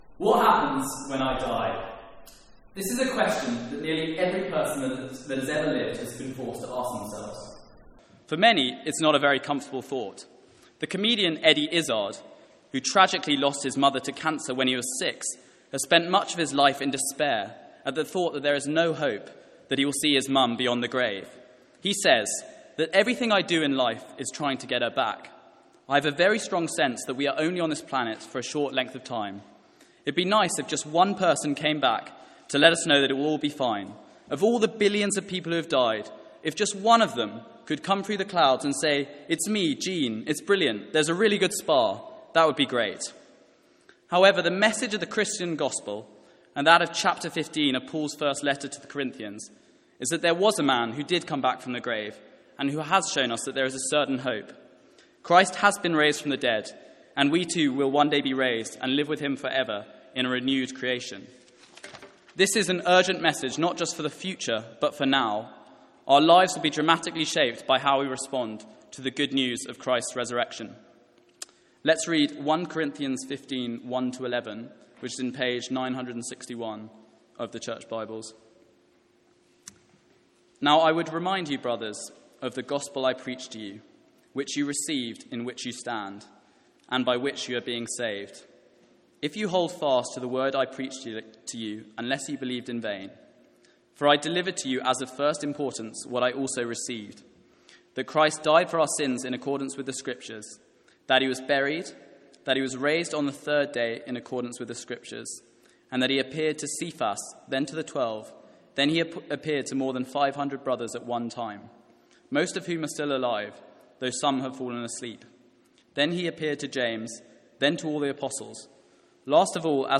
Sermons | St Andrews Free Church
From our evening series in 1 Corinthians.
Note: First few seconds re-recorded.